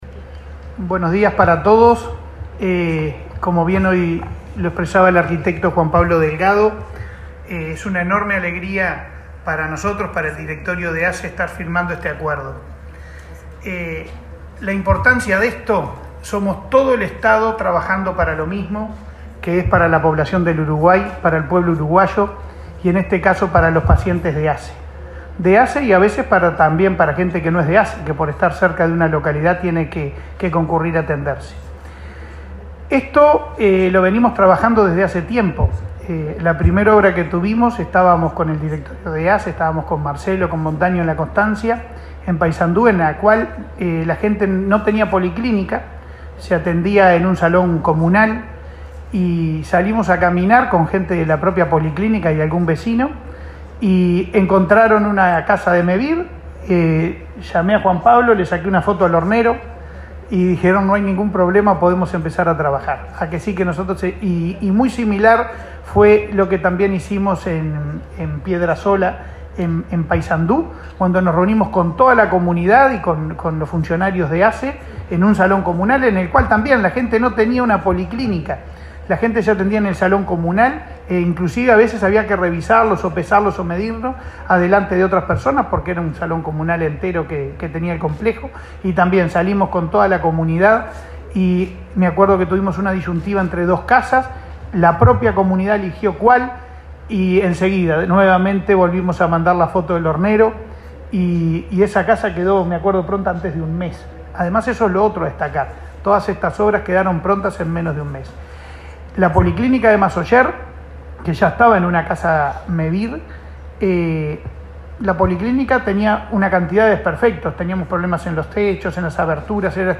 Declaraciones de Leonardo Cipriani sobre convenio entre ASSE y Mevir